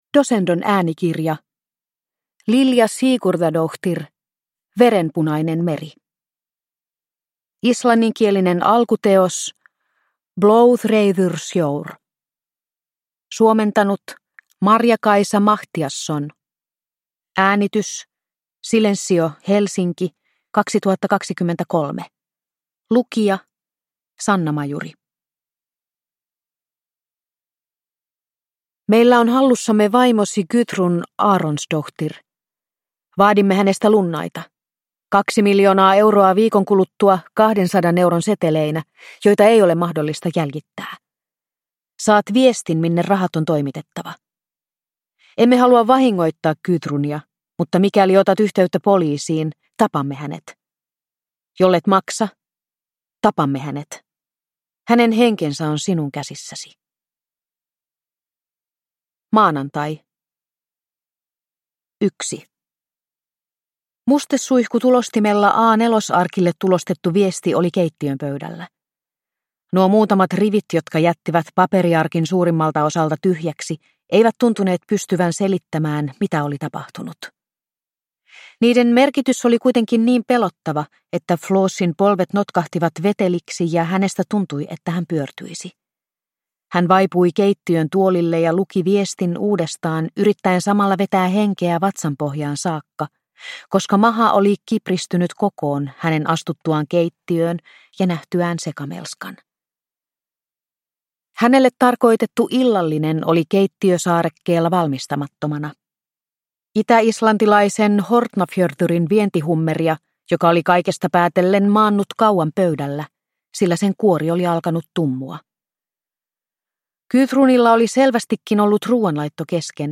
Verenpunainen meri – Ljudbok – Laddas ner